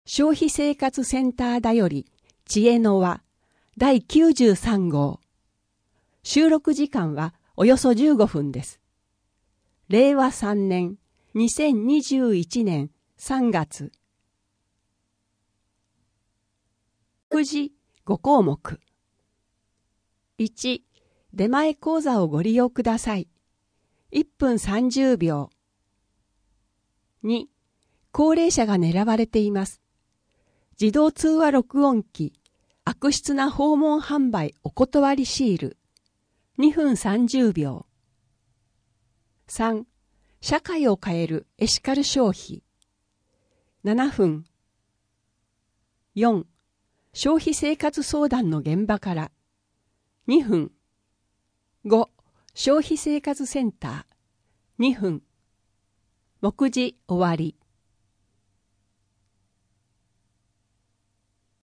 声の広報 声の広報は清瀬市公共刊行物音訳機関が制作しています。